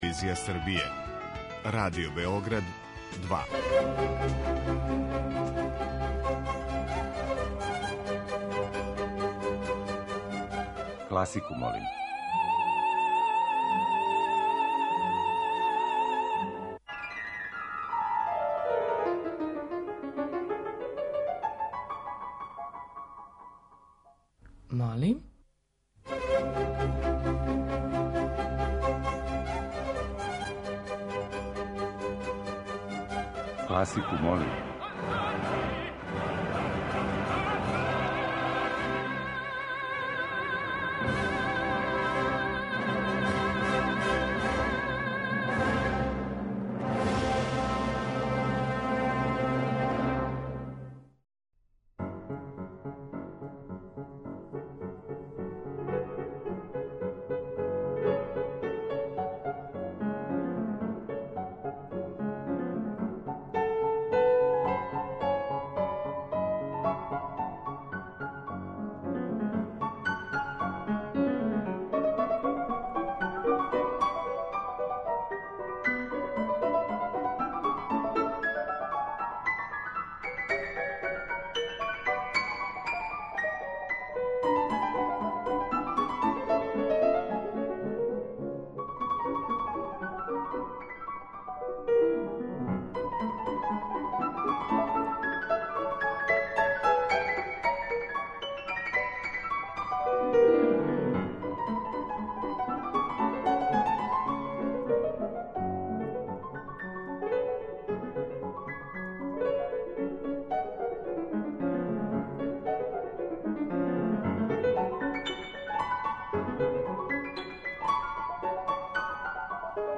Избор за топ-листу класичне музике Радио Београда 2